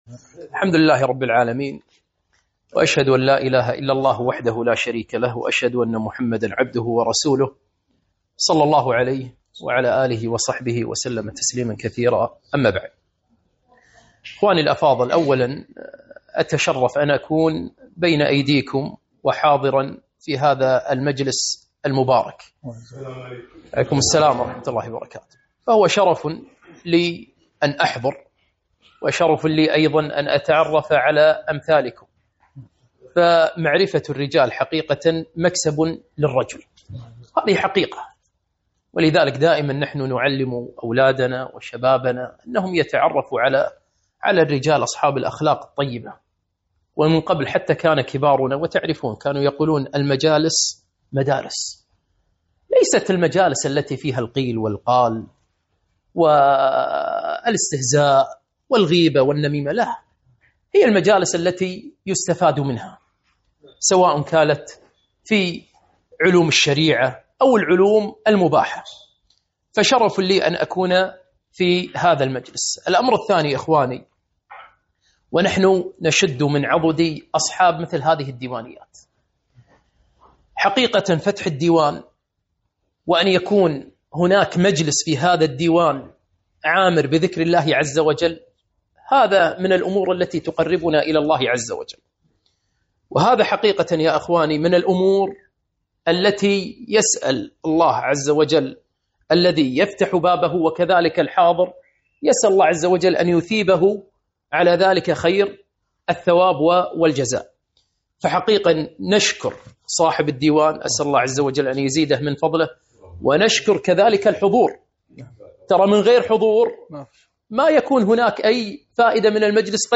محاضرة - نصائح مهمة في حياتنا اليومية